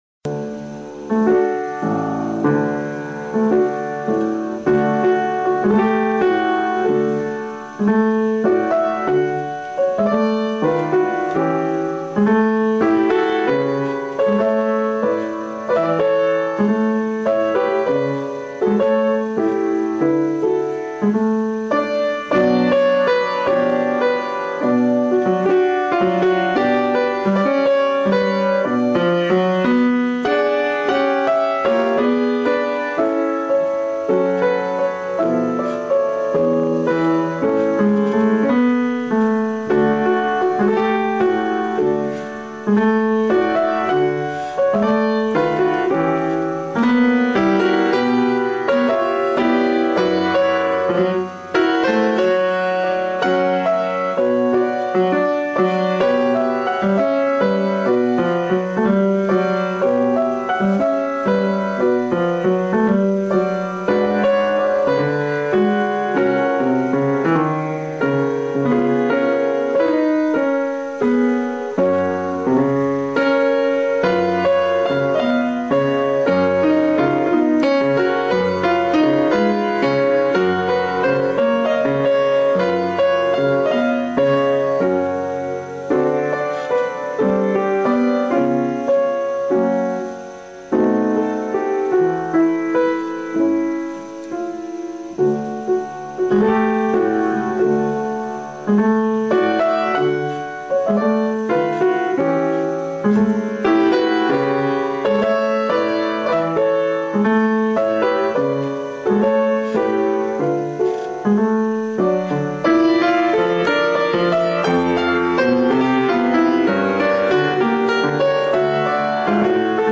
I've found a decent version of one of her favorite songs, but it has some static.
albeniz tango (simple eq).mp3